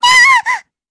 Lilia-Vox_Damage_jp_03.wav